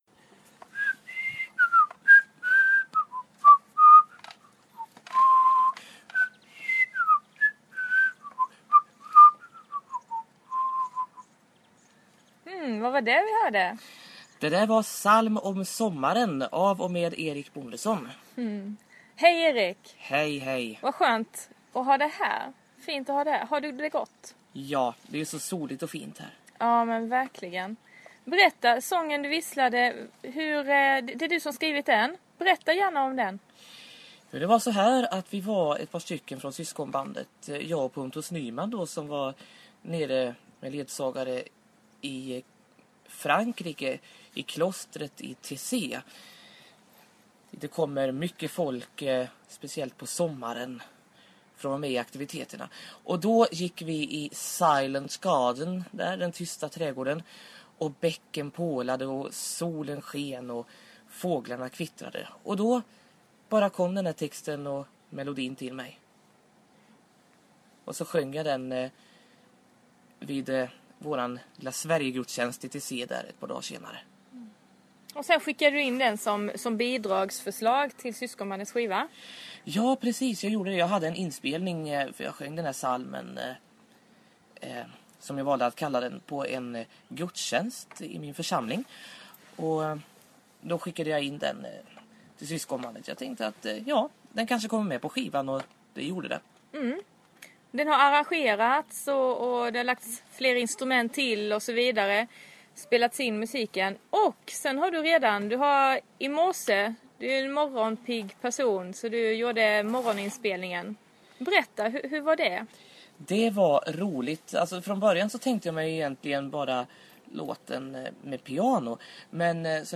Intervjuer
Under inspelningsdagarna gjordes det intervjuer med några av deltagarna som du kan ta del av här: